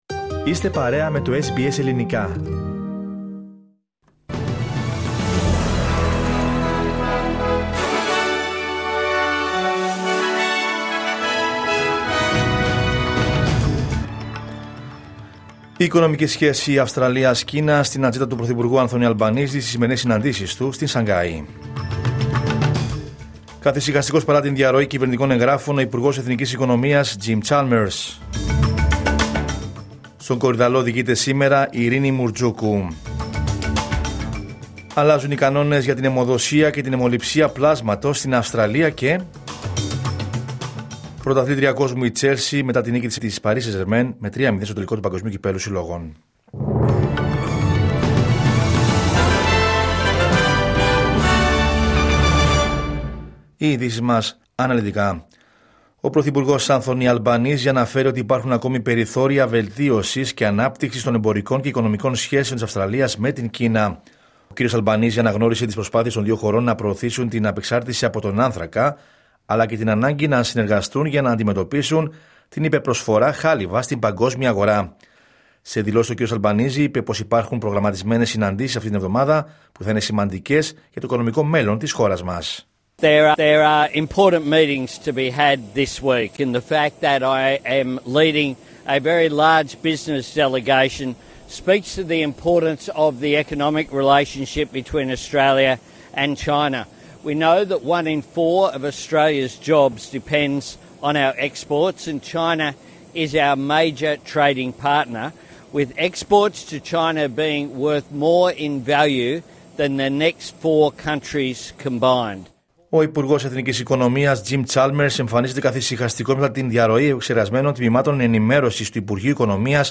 Δελτίο Ειδήσεων Δευτέρα 14 Ιουλίου 2025